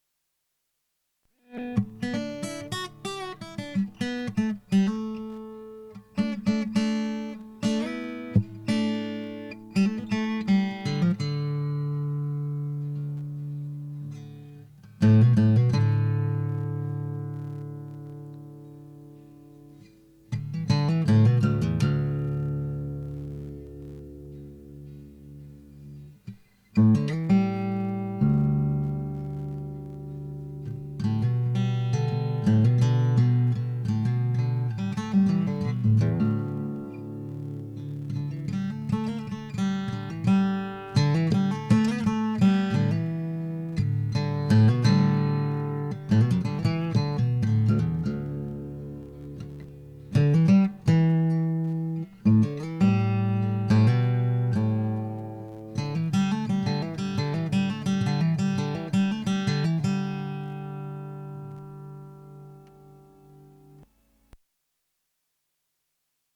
Problem with the Tascam porta02 - buzz issue
Today i started recording my own demo and whoop that buzz sound still persist - audio sample is attached.
It is very noticeable while monitoring input signal (without recording), less noticeable while tracking and then when monitoring the recorded material it is noticeable again.
I can clearly hear the static buzz.
The two channels aren't showing the static in the same places from what I could tell.
Yup, i also noticed that the right channel (or, the channel not being used for recording) is more buzzy, but it is also noticeable on the left channel.
it does not show up without any signal connected . but it's buzzing either way - via the mixer or simply mic into porta02. it seems to be more responsive to certain notes on the guitar (for example A and D), less to vocals, more the bass guitar..